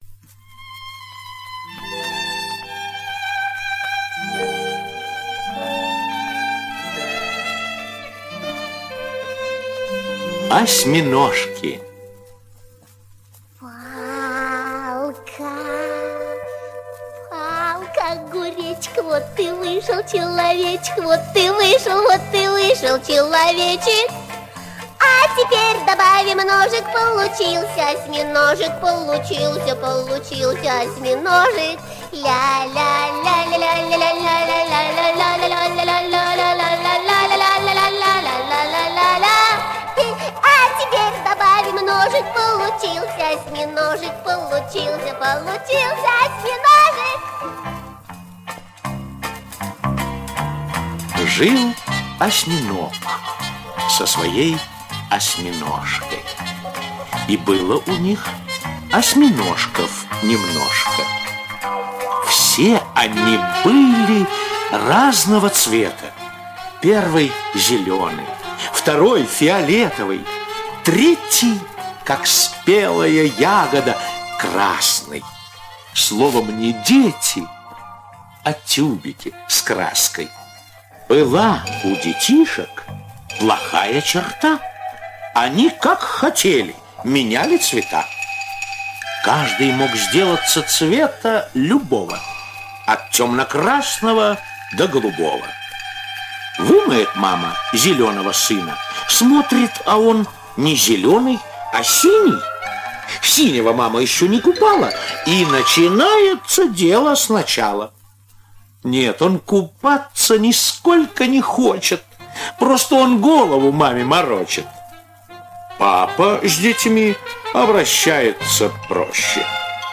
Осьминожки - аудиосказка Успенского Э.Н. Однажды папы-осьминоги перепутали малышей и поняли, что без мам-осьминожек им не разобраться.